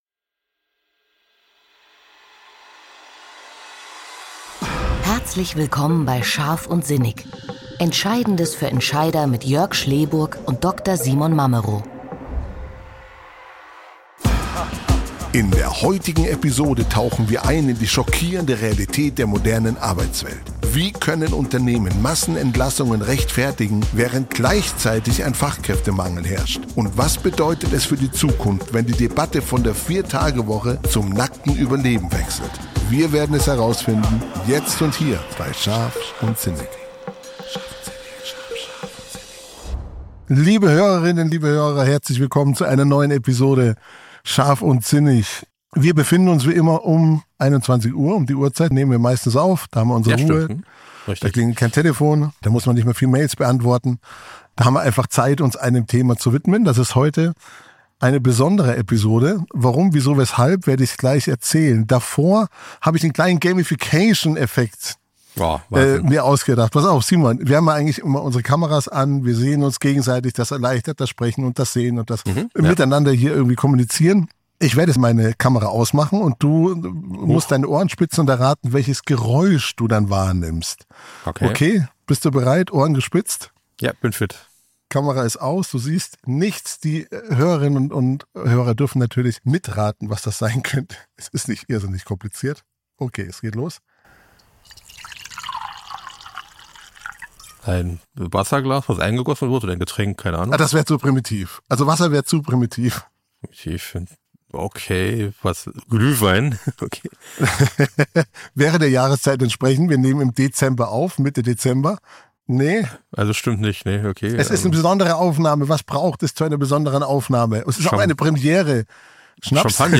Wir werfen einen Blick auf die bizarren Gegensätze: Frühruhestand mit 55 versus Rente mit 70, Vier-Tage-Woche versus Existenzängste. Dabei diskutieren wir, wie Digitalisierung und demografischer Wandel den Arbeitsmarkt radikal verändern und warum Personalentwicklung zum Rettungsanker werden könnte.